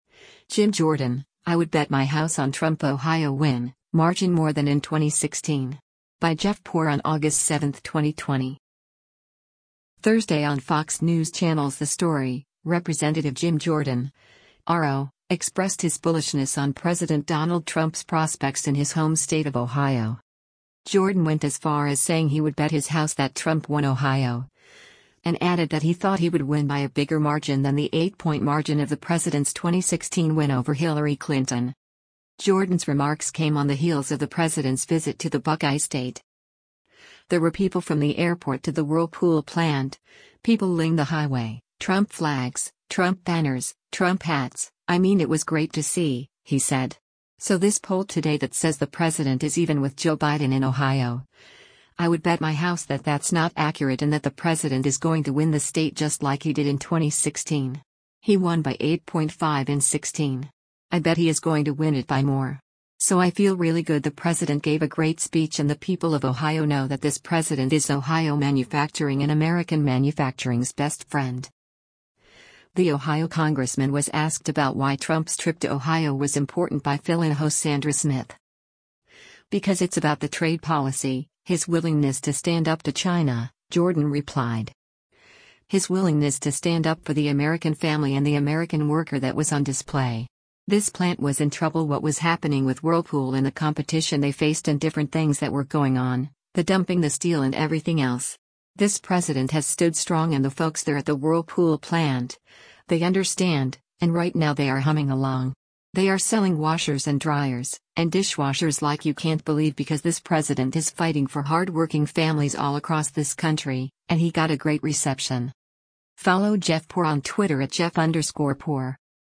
The Ohio congressman was asked about why Trump’s trip to Ohio was important by fill-in host Sandra Smith.